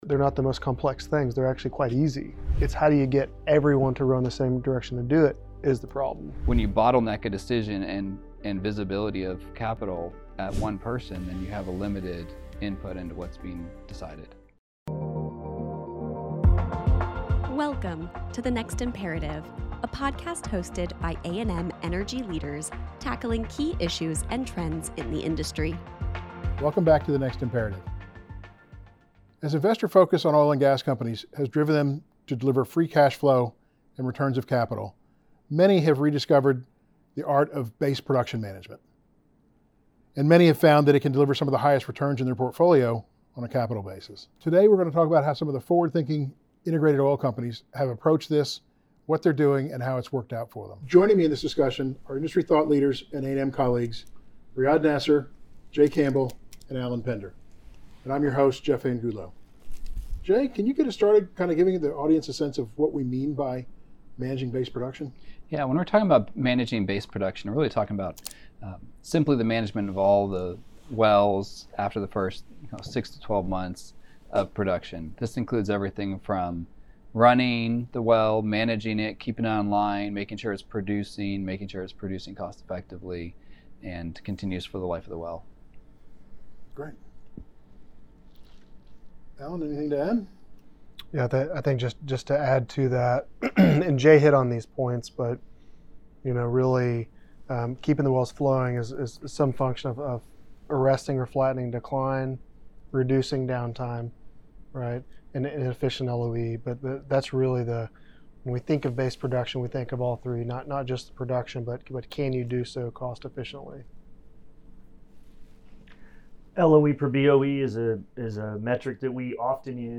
Listen to a conversation about managing base production and the opportunity for improvement at the drilling site. Learn how to optimize your capital and workforce to make your well the most profitable it can be!